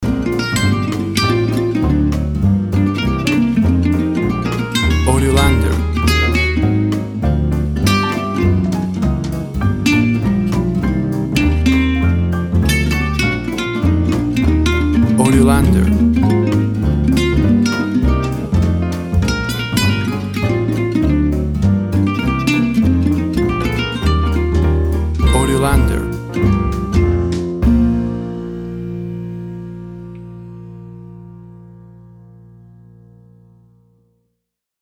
Smooth jazz piano mixed with jazz bass and cool jazz drums.
WAV Sample Rate 16-Bit Stereo, 44.1 kHz
Tempo (BPM) 200